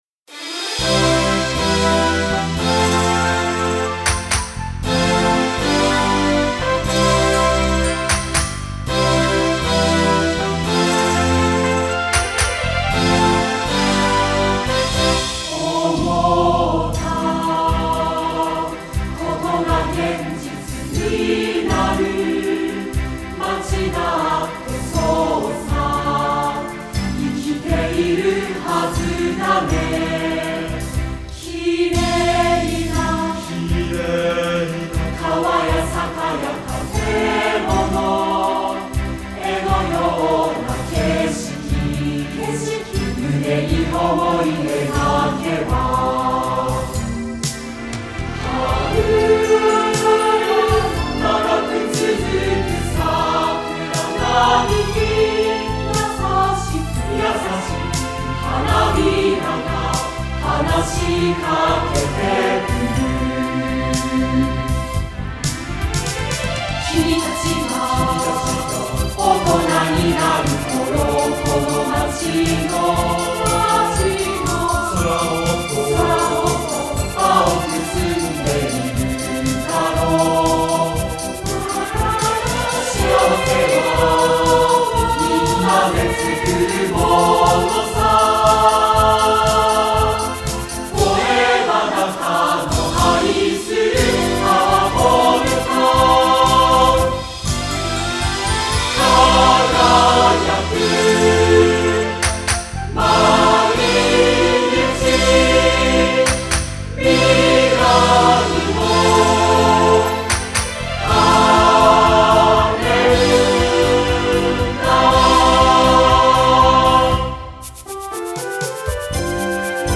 2015年3月11日　東亜学園ホールにて録音
合唱（音楽ファイル(MP3)：9,742KB）